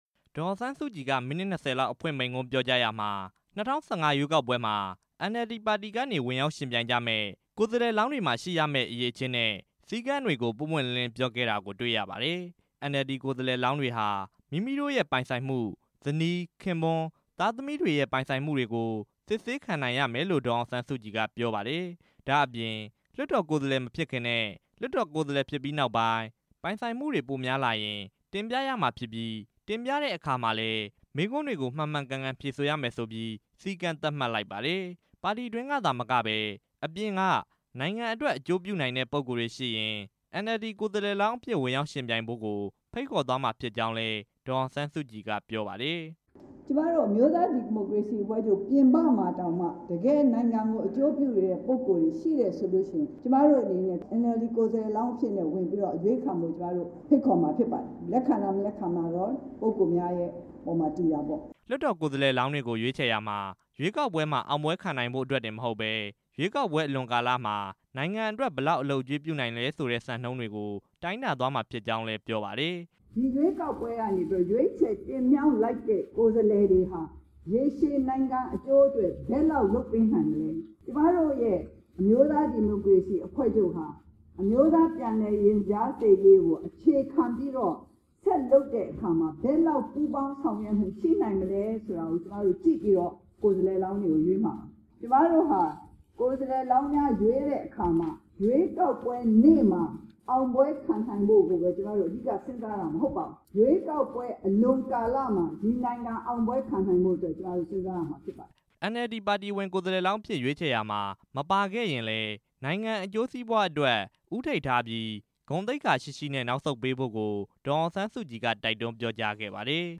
အမျိုးသား ဒီမိုကရေစီအဖွဲ့ချုပ် ဗဟိုကော်မတီ အစည်းအဝေးကို ရန်ကုန်မြို့ ဗဟန်းမြို့နယ်က တော်ဝင်နှင်းဆီမှာ ဒီနေ့စတင်ကျင်းပရာမှာ ၂ဝ၁၅ ရွေးကောက်ပွဲ ဝင်ရောက်ယှဉ်ပြိုင်မယ့် ကိုယ်စားလှယ်လောင်းတွေ လိုက်နာရမယ့် အချက်တွေနဲ့ အရည်အချင်းတွေကို NLD ပါတီ ဥက္ကဋ္ဌ ဒေါ်အောင်ဆန်းစုကြည်က အဓိထား ပြောခဲ့ပါတယ်။